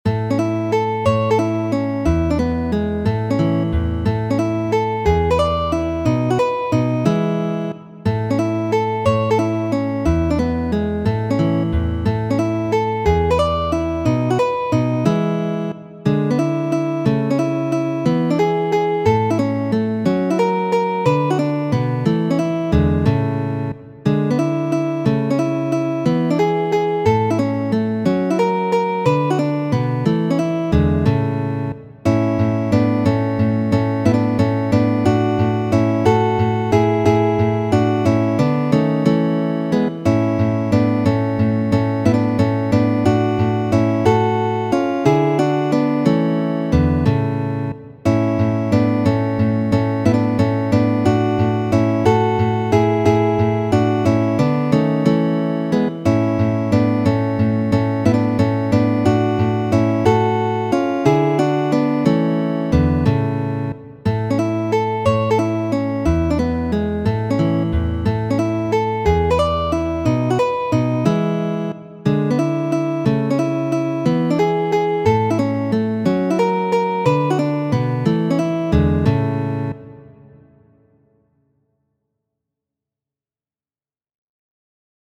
Doce variaciones para guitarra, de Dionisio Aguado García (fragmento).